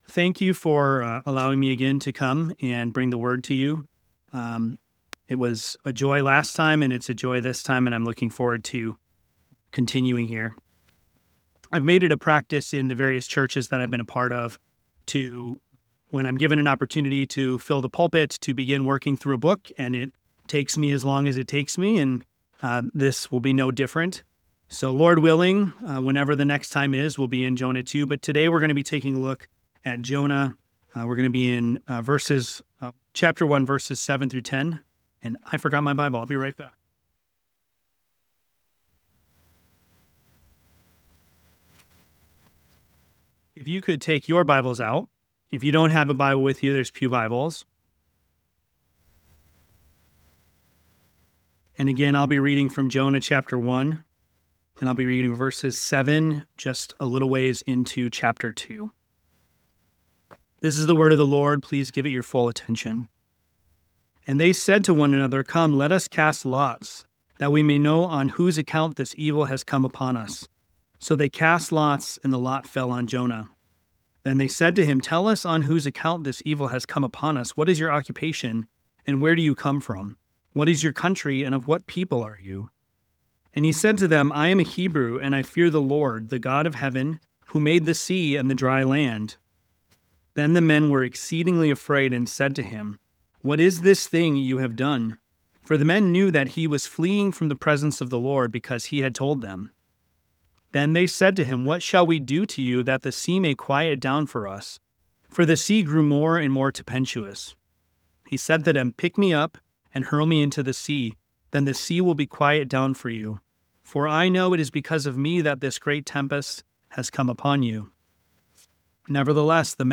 He challenges Christians to reflect on their spiritual lives, evangelize their children and neighbors, and pray for all people, including those who may seem far from God. The sermon serves as a call to action, urging believers to grasp the urgency of sharing the gospel and living in light of their new life in Christ.